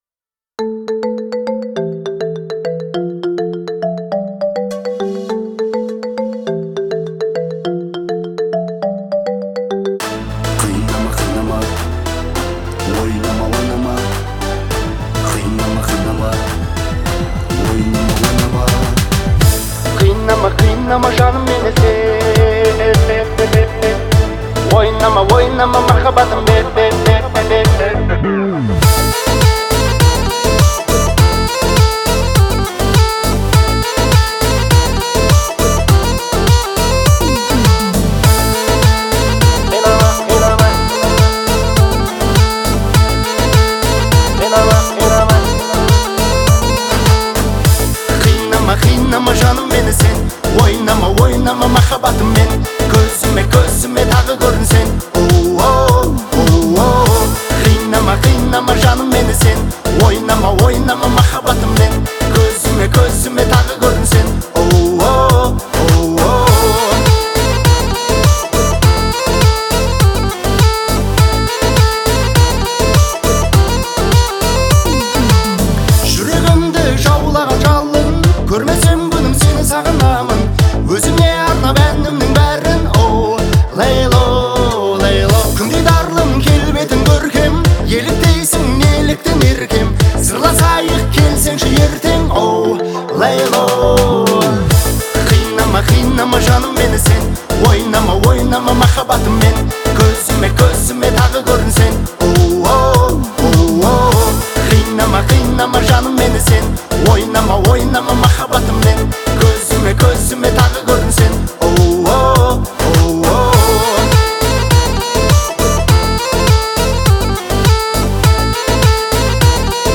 харизматичным исполнением и эмоциональной подачей